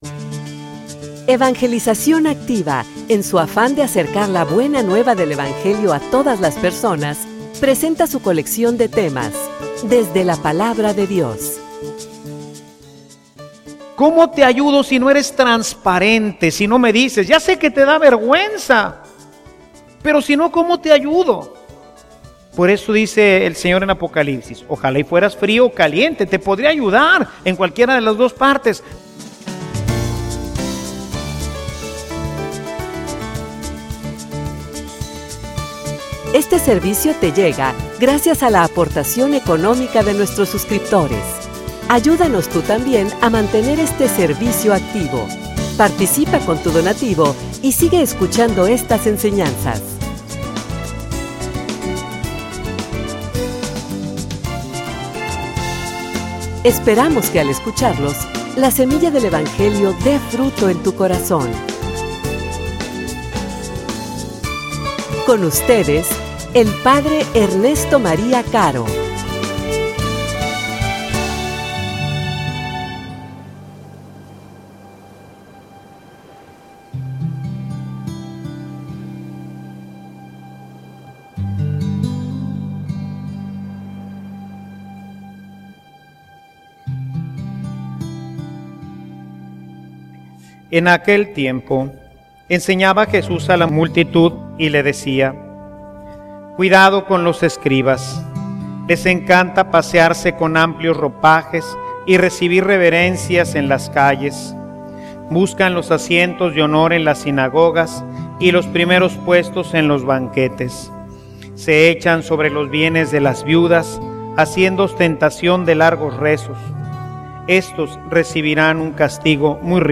homilia_Purifica_mi_corazon.mp3